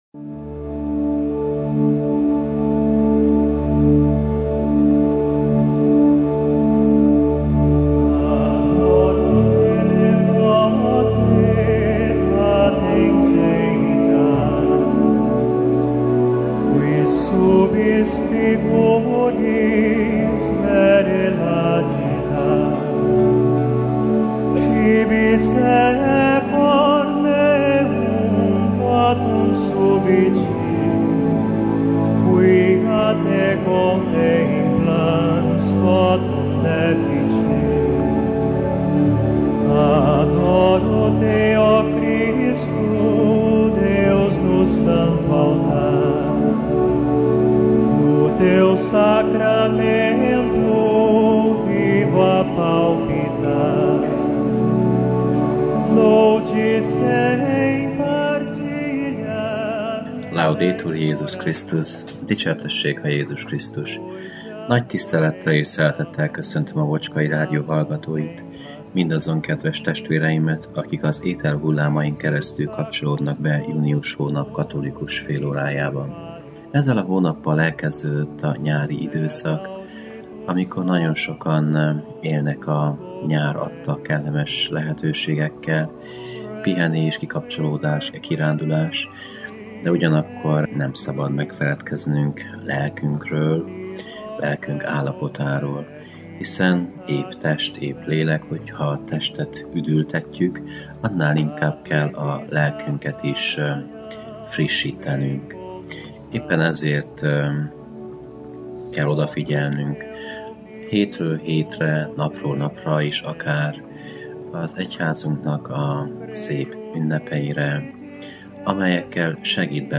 Igét hirdet
a clevelandi Szent Imre Katolikus Templomból.